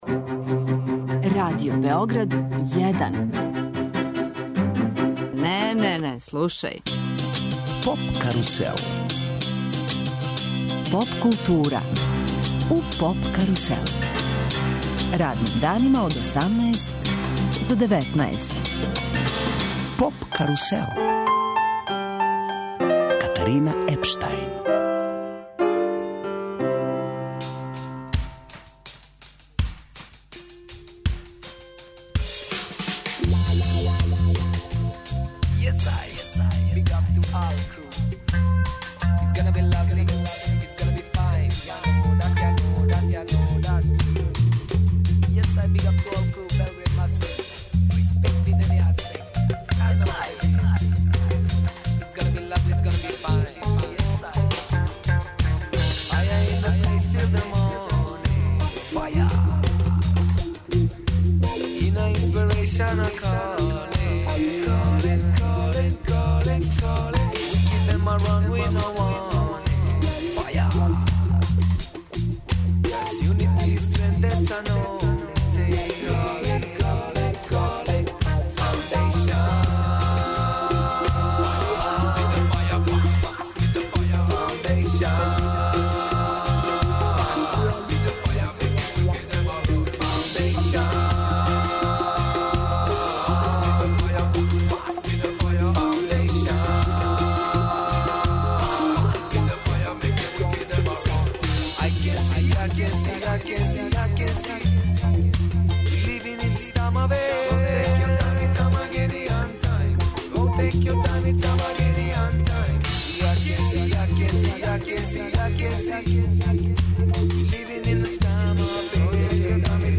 EXIT и Радио Београд 1, у оквиру програма CEETEP: гости емисије биће чланови састава Ајсбрн.